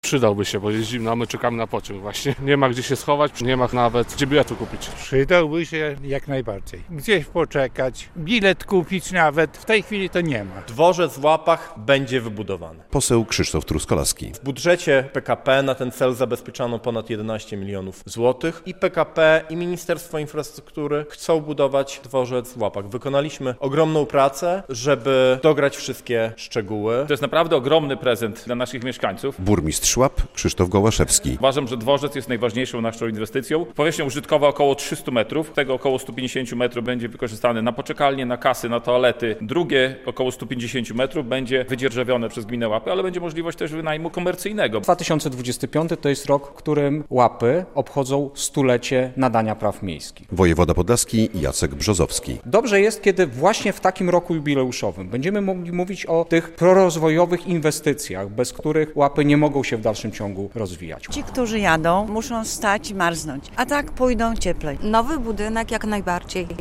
Nowy dworzec w Łapach - relacja